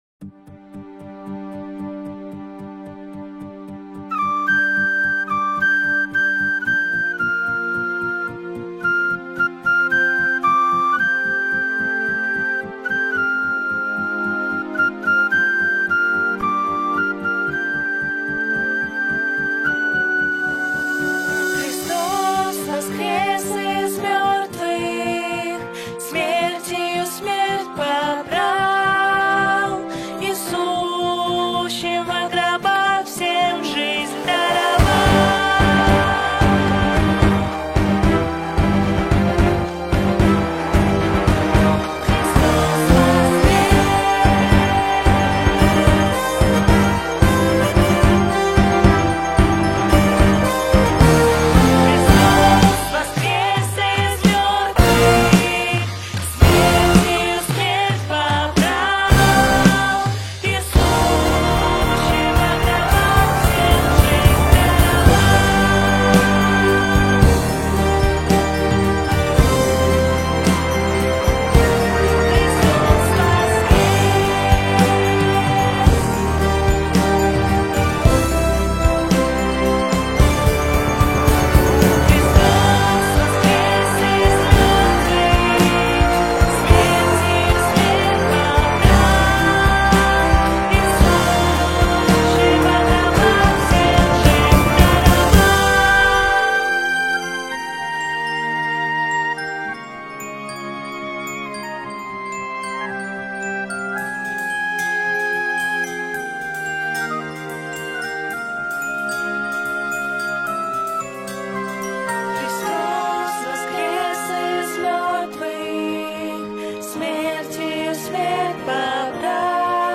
Вокальный диапазон C1->G1
Соло флейты:
185 просмотров 321 прослушиваний 6 скачиваний BPM: 111